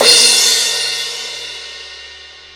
J   CRASH 1.wav